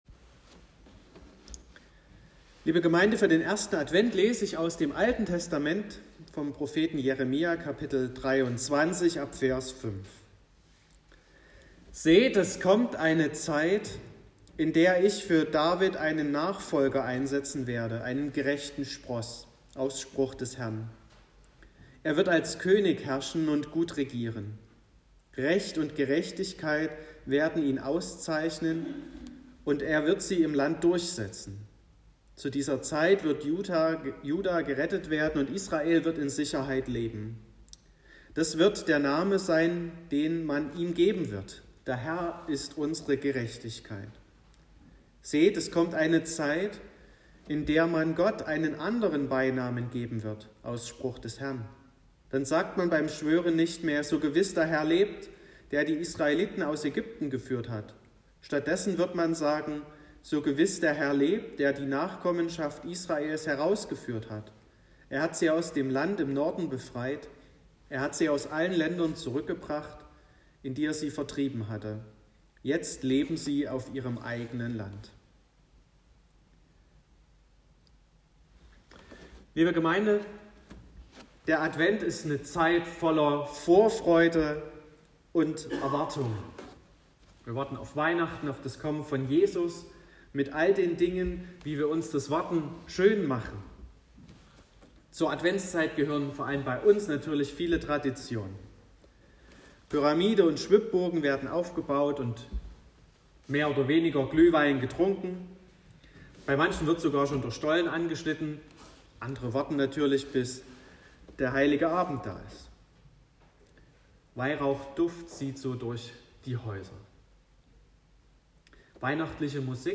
28.11.2021 – Gottesdienst
Predigt (Audio): 2021-11-28_3_Koenige.m4a (5,8 MB)